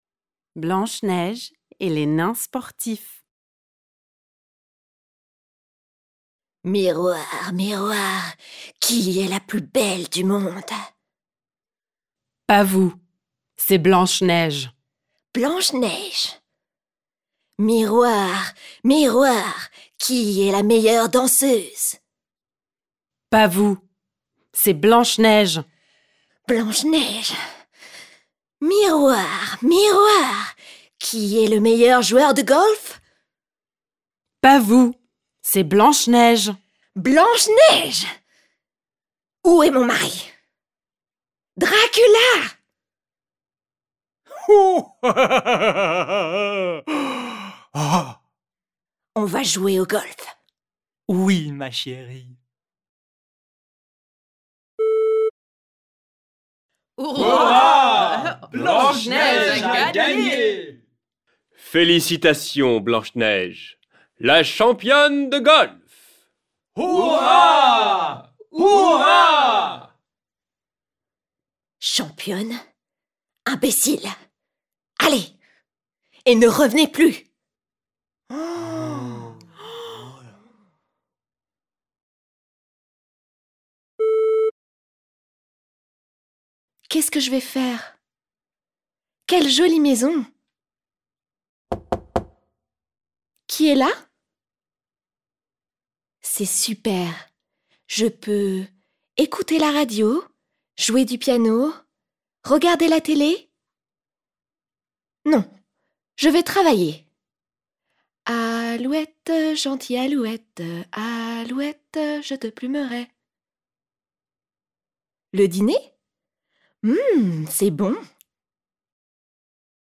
The CD-Rom included with the book contains audio files of native French speakers performing the plays as well as a pdf version of the book.